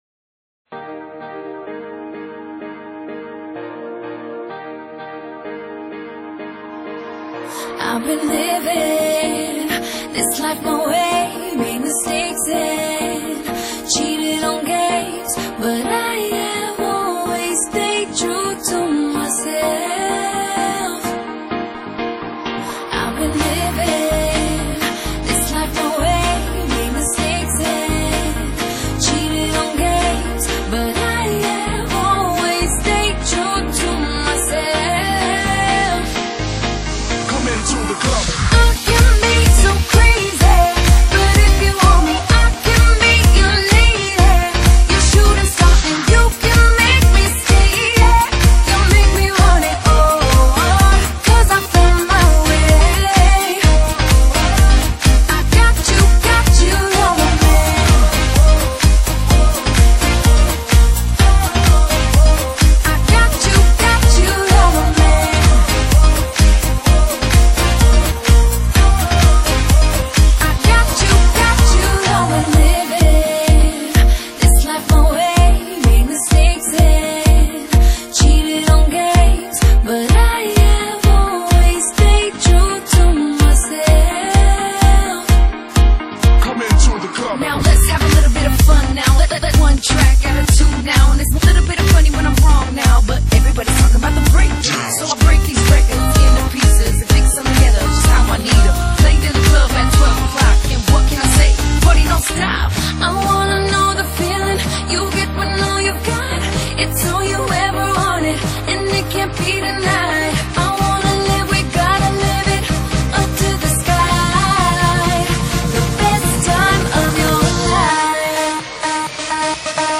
Genre: Dance, Pop |22 Tracks | (百度盤)=218 M